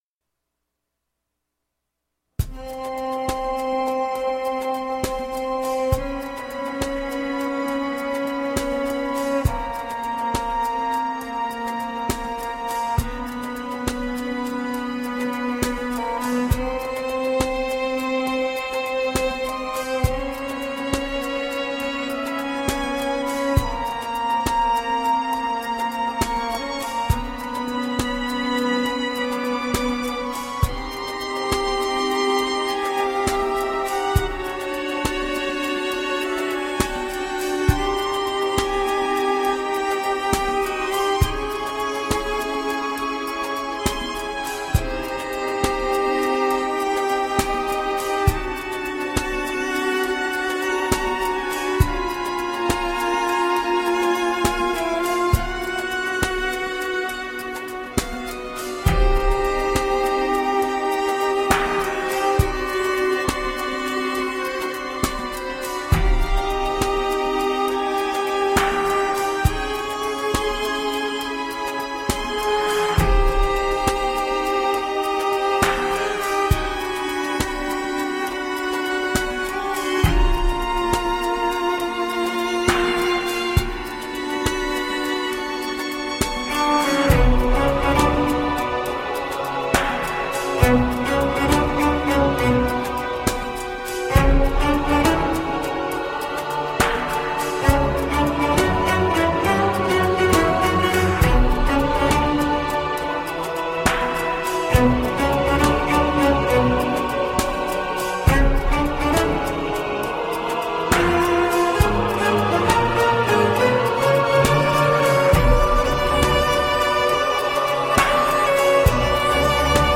New age meets indian cello.
Tagged as: New Age, World